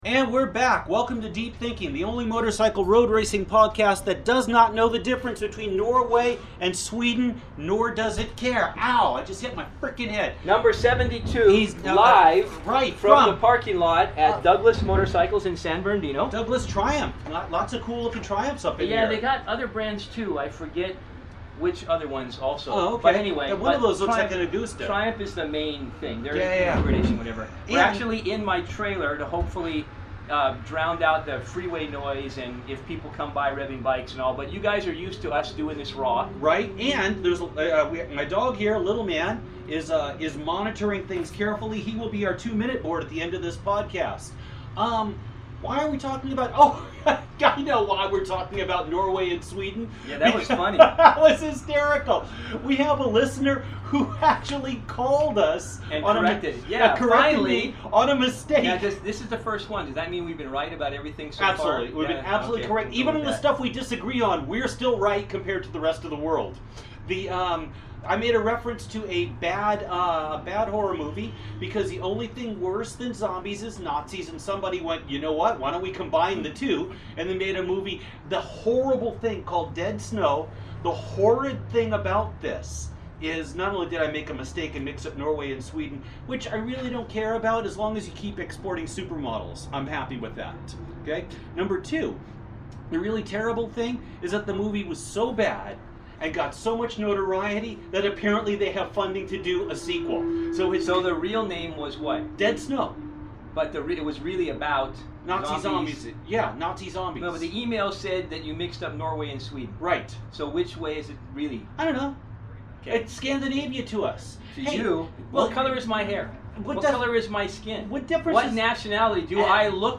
but once again find themselves ruminating on the state of racing in the familiar comfort of the mobile Lindemann Engineering shop. A discussion ensues about Norway vs. Sweden in the context of a Nazi zombie movie which has, apparently, spawned a sequel.